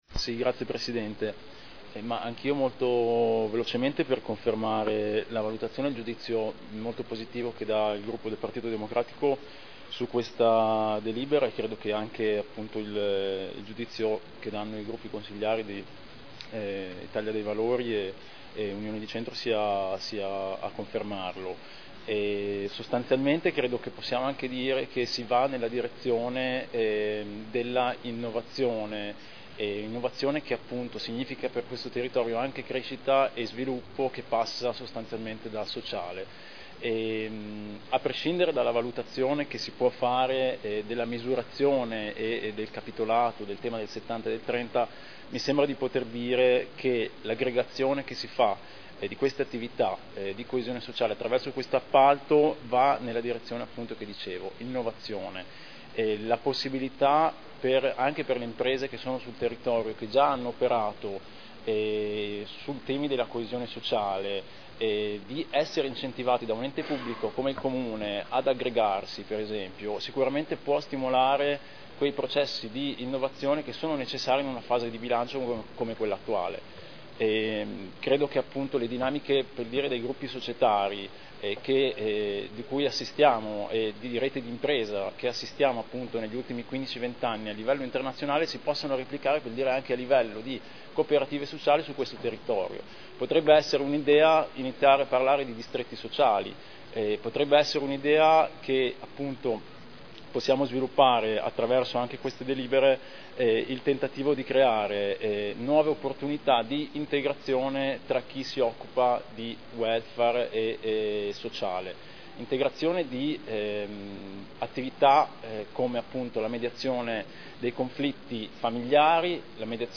Stefano Rimini — Sito Audio Consiglio Comunale
Seduta del 19/09/2011. Dichiarazione di voto proposta di deliberazione.